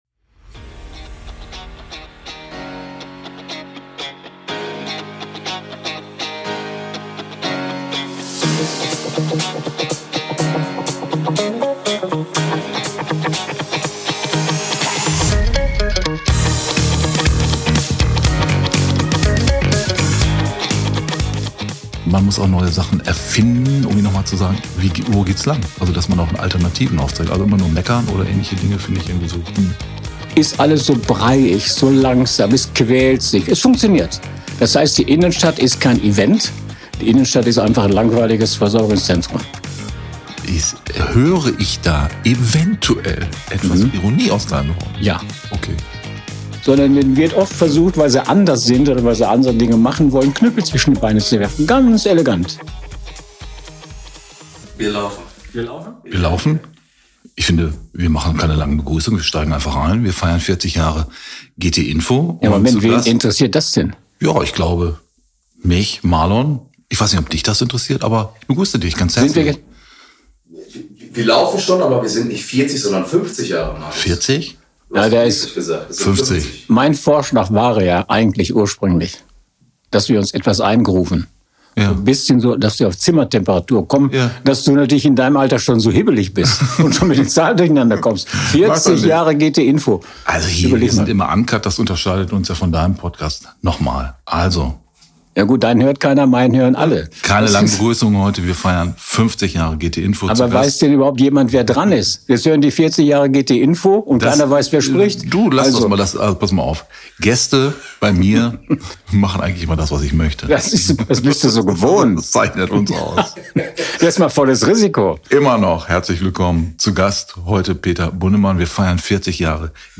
Ein Gespräch, das zeigt: In Gütersloh brodelt es mehr als man denkt, und das gt!nfo ist mittendrin statt nur dabei.